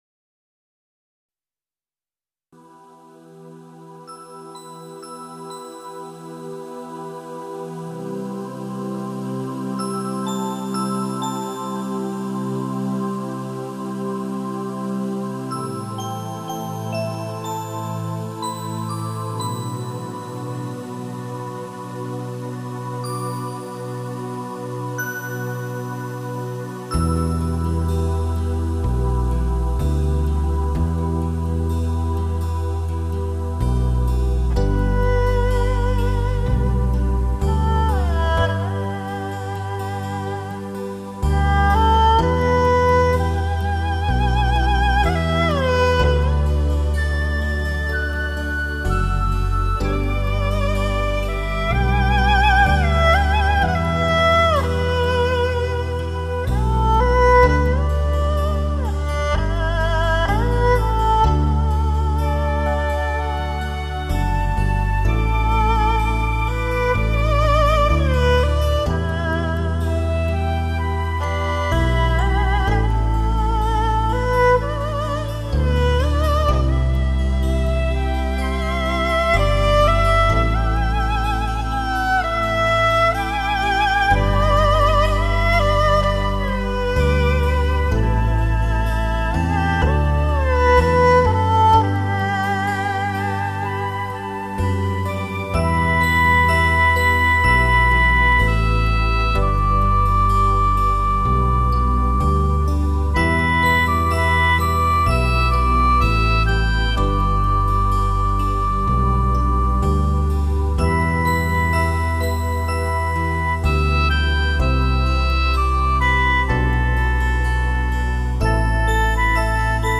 带着中国民族音乐浓郁风味的经典新世纪休闲音乐，绝对的超值珍藏品。